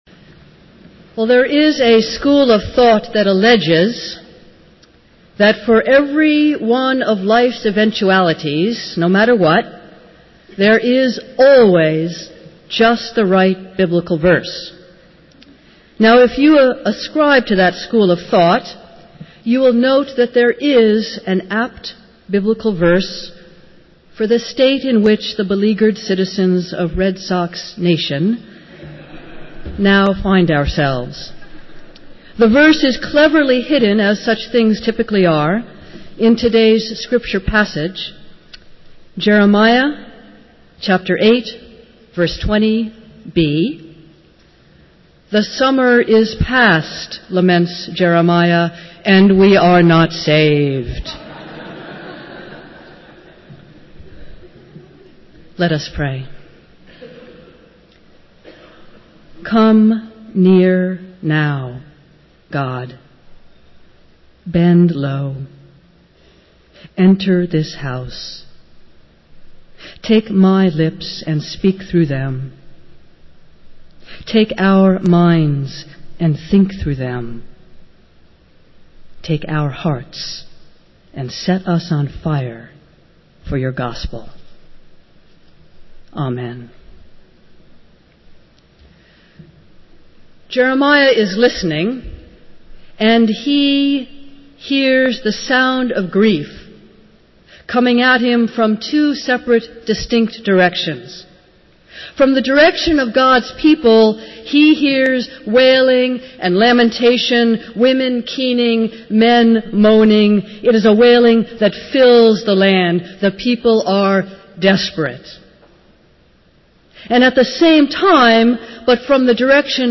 Festival Worship - Congregational Care & Support Sunday